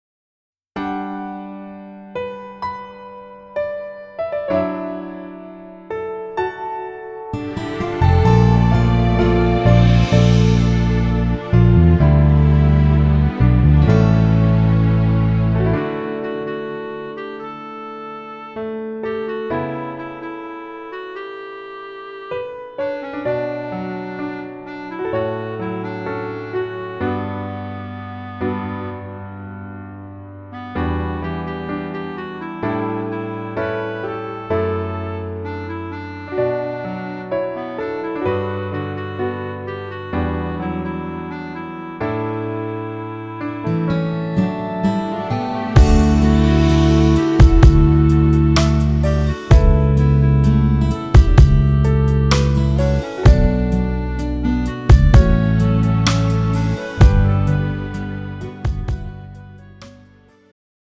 음정 원키
장르 축가 구분 Pro MR